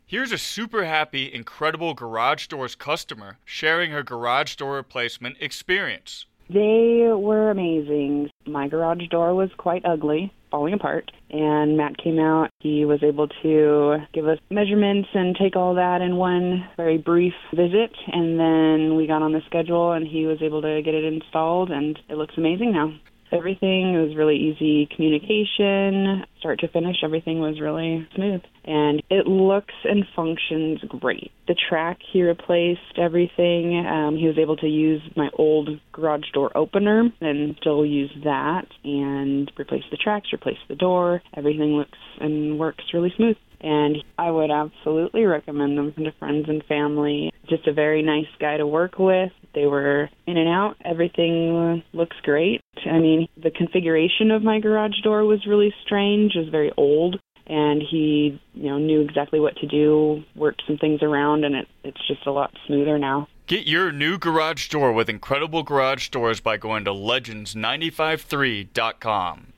Hear What Their Customers Say:
incredilbe-testimonial-final.mp3